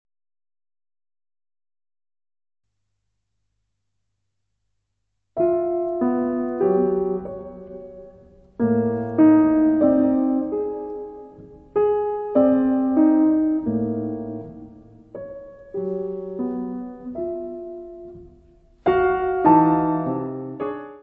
: stereo; 12 cm
Music Category/Genre:  Classical Music